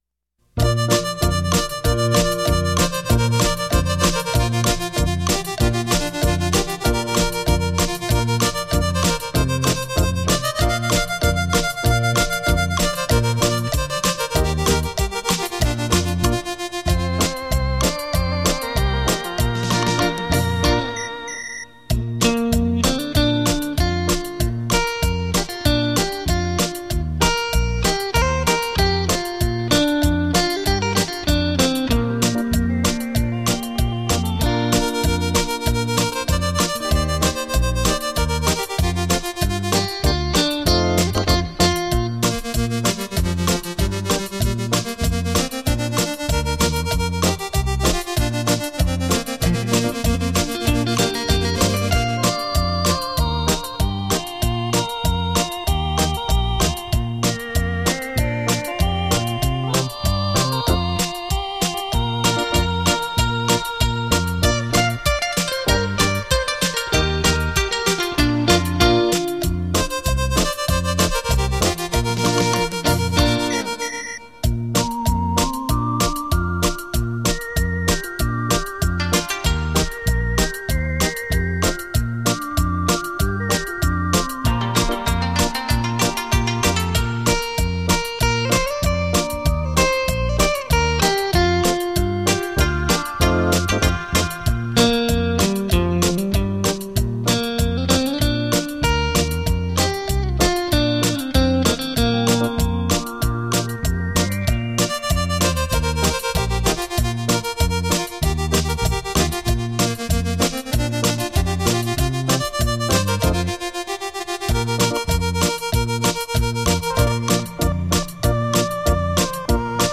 数位录音 品质保证
吉鲁巴